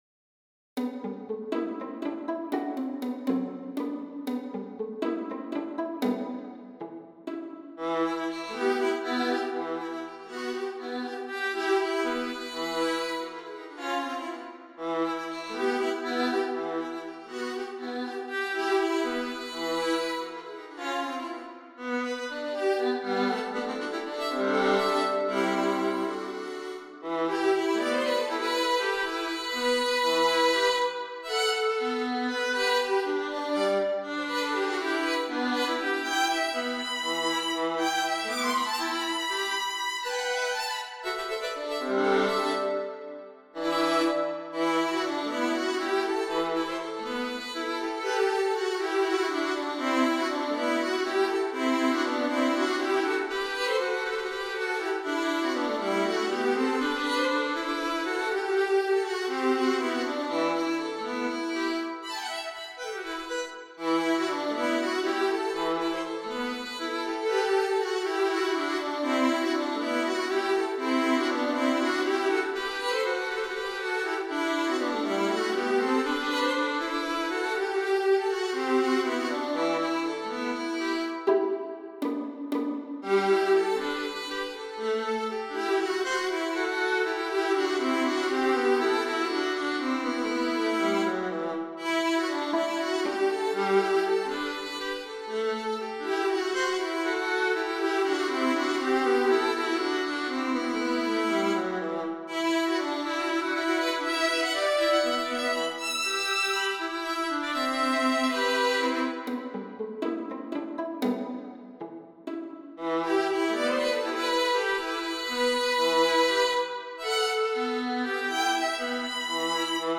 A traditional round dance in 7/8, often heard in Serbia -- "Ajde Jano, kolo da igramo.
3 pages, circa 3' 00" - an MP3 demo is here: